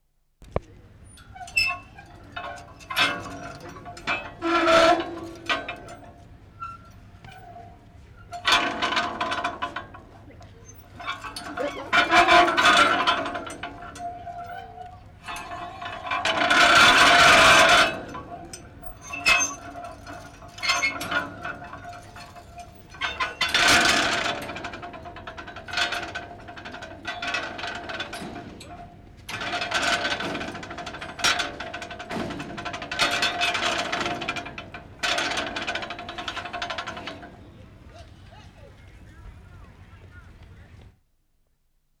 MORE PLAYGROUND SOUNDS (Metal Propeller) (0'40")
Metal "submarine" propeller, hand operated, very loud, grinding and clangorous. Interesting rhythms.